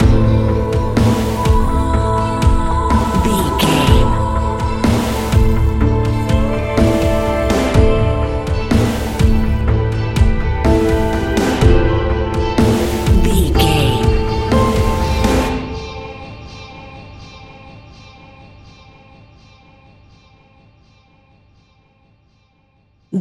Ionian/Major
electronic
techno
trance
synths
synthwave
glitch
instrumentals